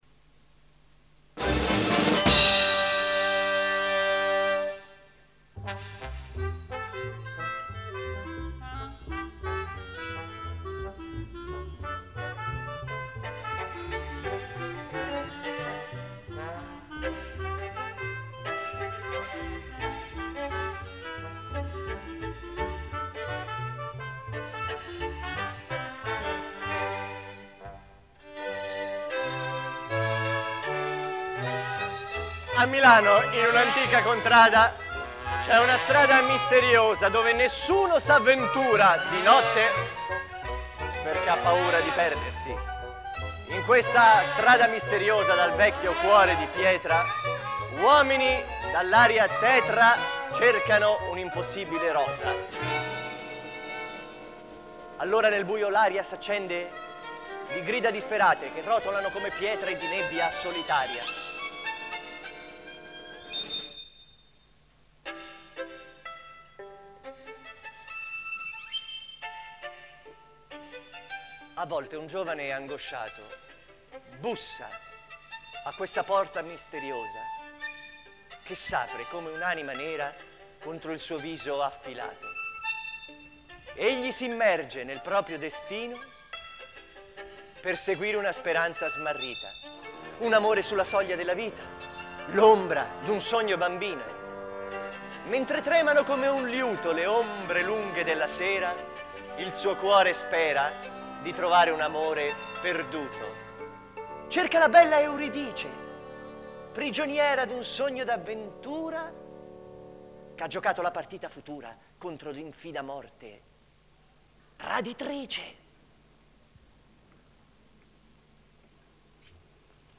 sopran
bariton
speaker